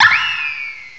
sovereignx/sound/direct_sound_samples/cries/mienshao.aif at 6b8665d08f357e995939b15cd911e721f21402c9